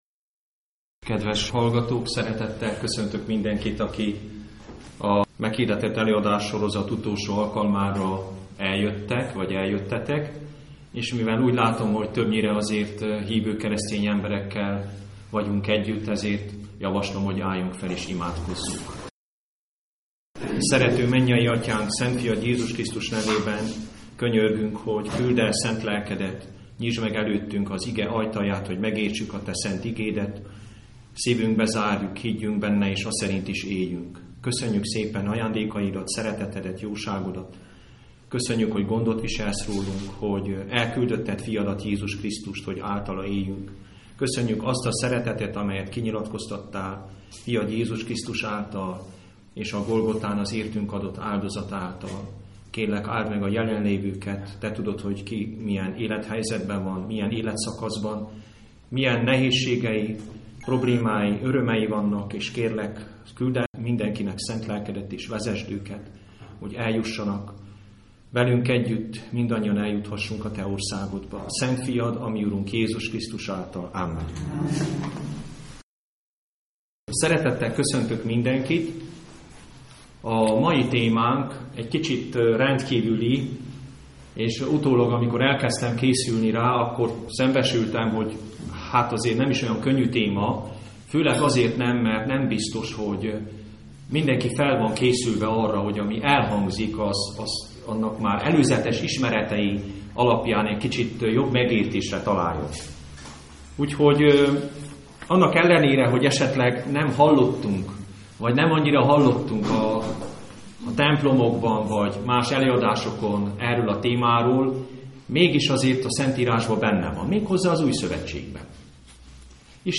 A Szentlélek adományairól szóló előadás itt hallgatható meg!